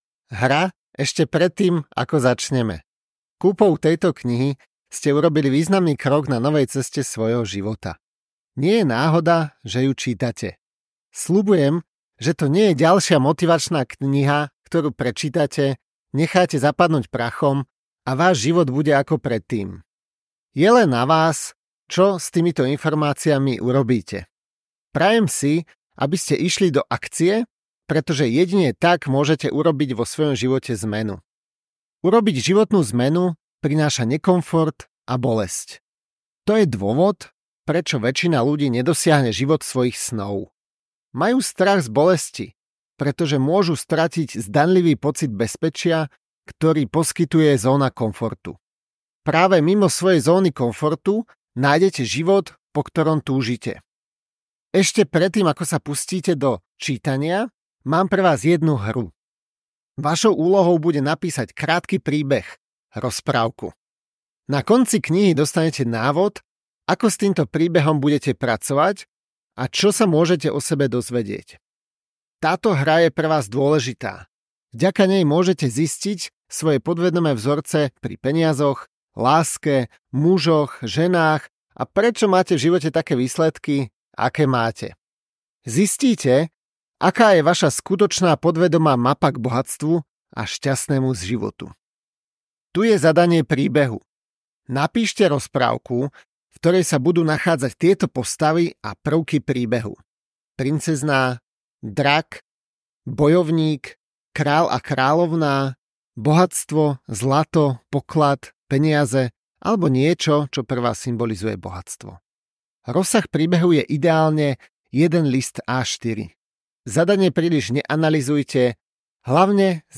Mapa k bohatstvu audiokniha
Ukázka z knihy
mapa-k-bohatstvu-audiokniha